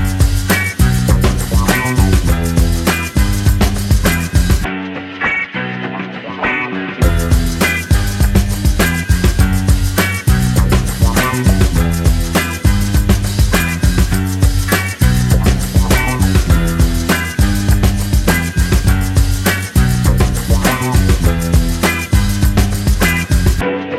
No Backing Vocals R'n'B / Hip Hop 3:34 Buy £1.50